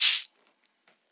• Long Tail Reverb Shaker Sound C Key 36.wav
Royality free shaker tuned to the C note. Loudest frequency: 8200Hz
long-tail-reverb-shaker-sound-c-key-36-lhb.wav